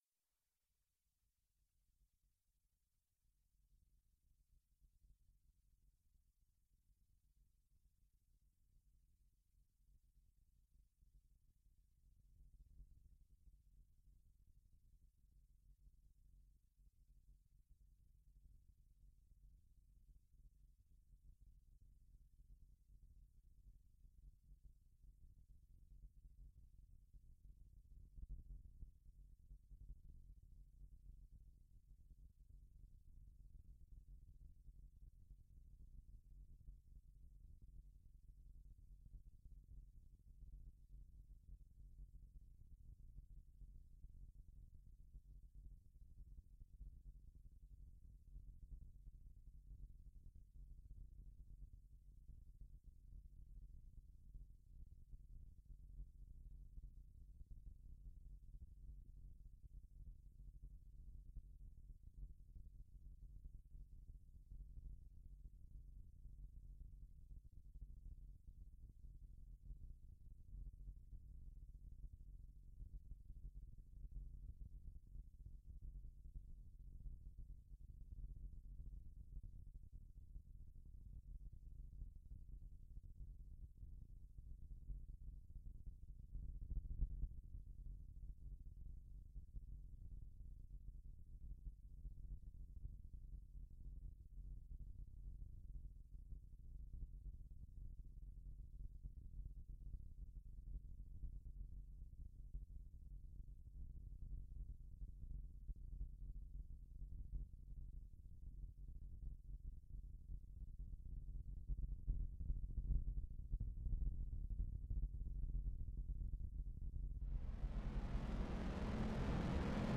À aucun moment, nous n'utilisons de samples ou autres artéfacts sonores. Même si l'onde est amplifiée, on garde sa même énergie et sa temporalité car seule la bande des fréquences allant de 30 Hz à 100 Hz (gamma), peut être perçue directement par l'oreille humaine, sans transformation.